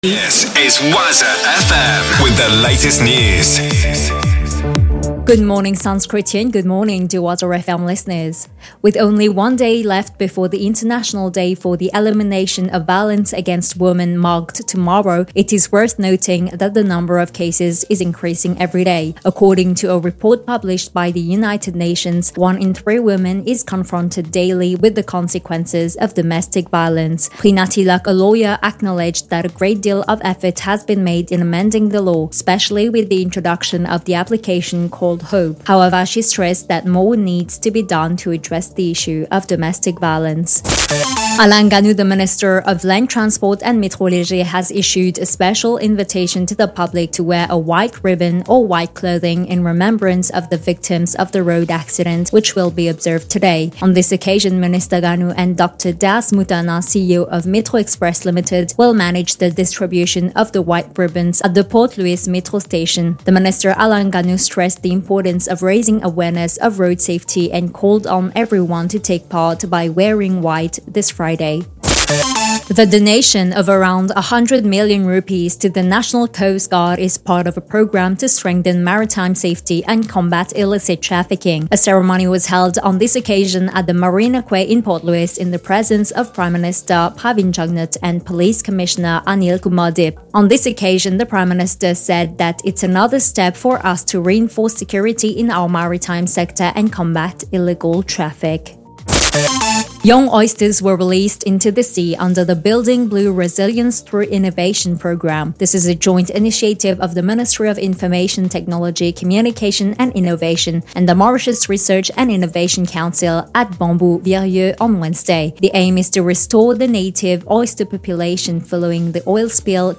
NEWS 9H - 24.11.23